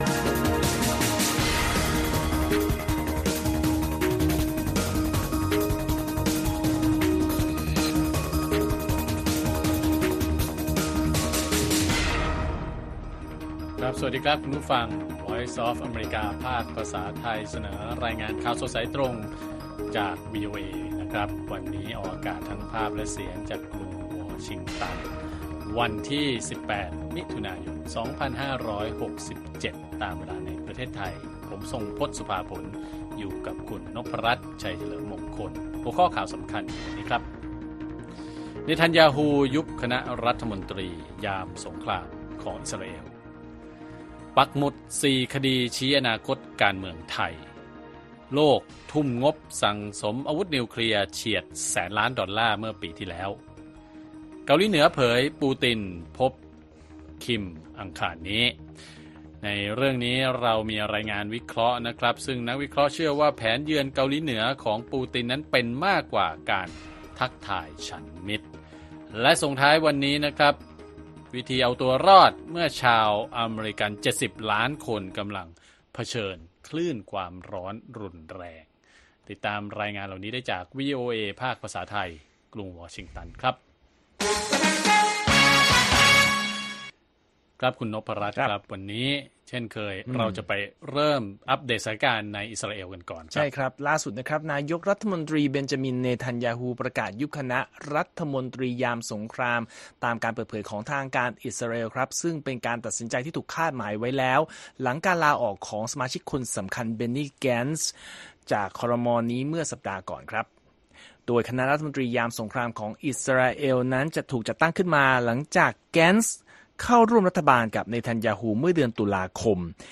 ข่าวสดสายตรงจากวีโอเอไทย 6:30 – 7:00 น. วันอังคารที่ 18 มิถุนายน 2567